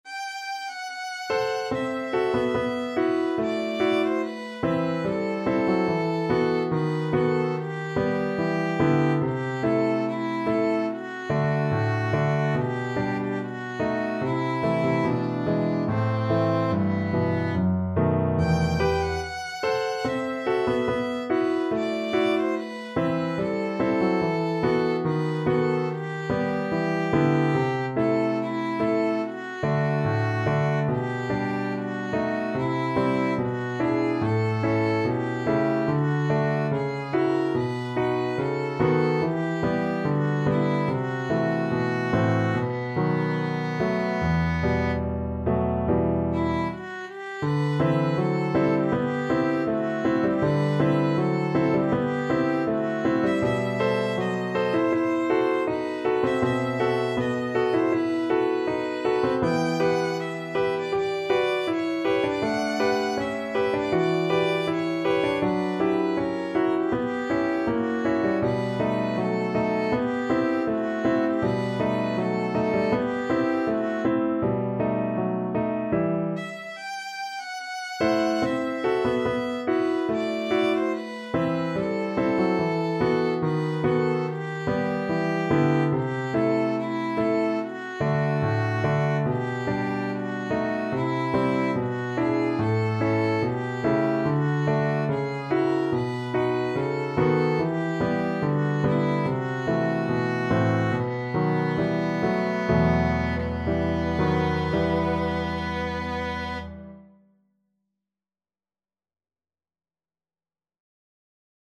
2/4 (View more 2/4 Music)
~ = 72 Andantino (View more music marked Andantino)
B4-G6
Classical (View more Classical Violin Music)